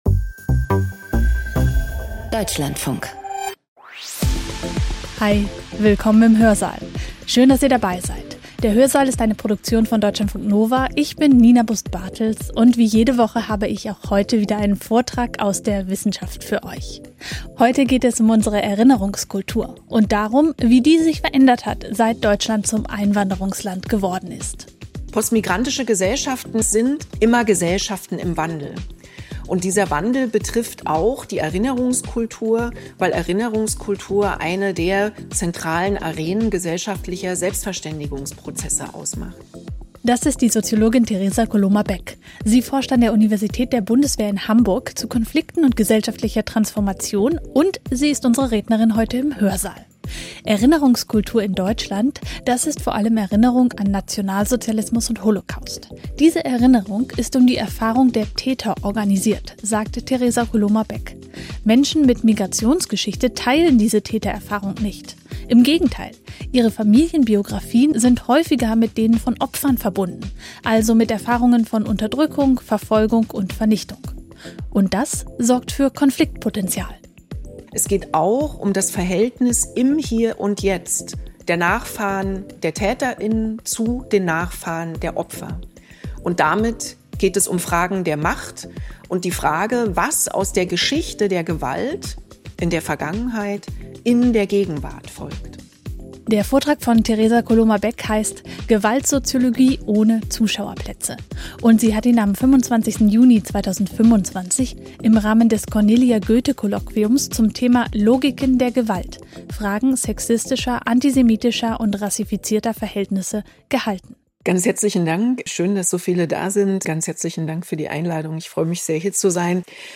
Feature Podcast abonnieren Podcast hören Podcast Zeitfragen-Feature Unsere Welt ist komplex, die Informationsflut überwältigend.